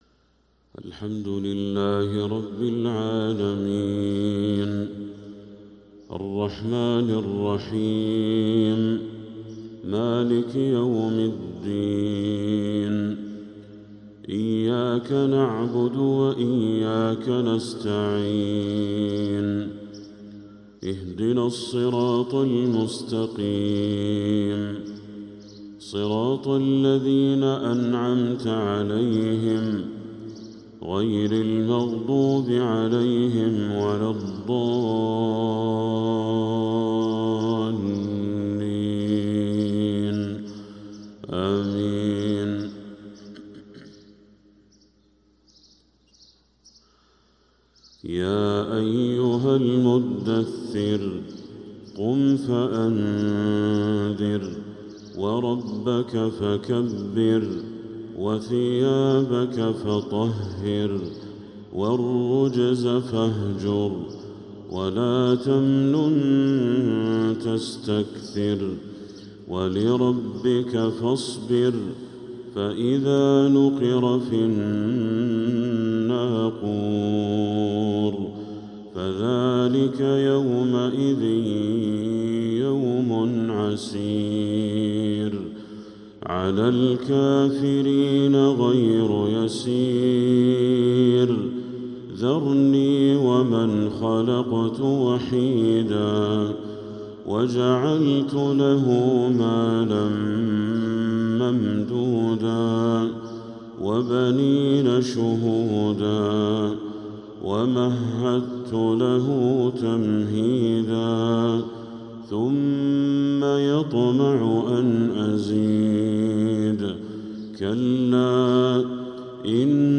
فجر الخميس 2-7-1446هـ سورة المدثر كاملة | Fajr prayer from Surat Al-Muddathir 2-1-2025 > 1446 🕋 > الفروض - تلاوات الحرمين